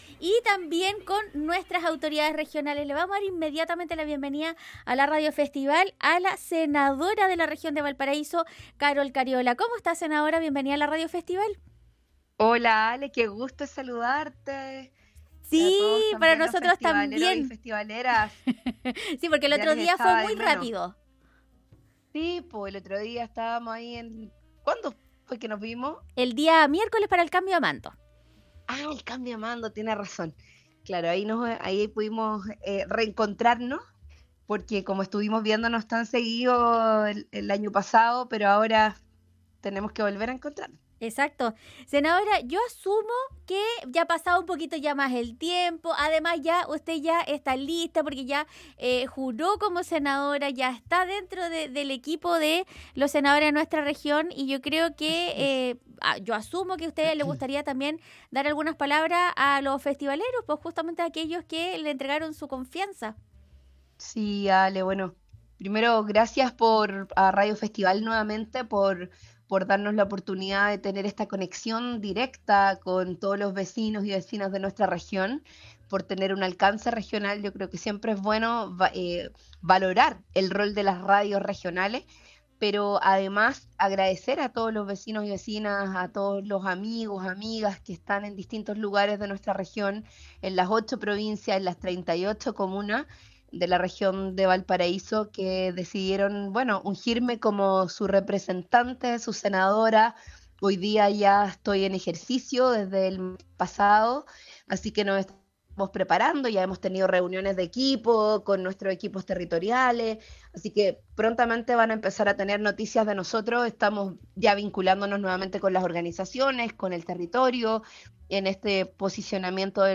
La Senadora de la zona conversó con Radio Festival sobre los lineamientos que tendrá su período legislativo con miras en proyectos regionales, así como estará pendiente con los eventuales cambios en áreas de salud, educación y vivienda.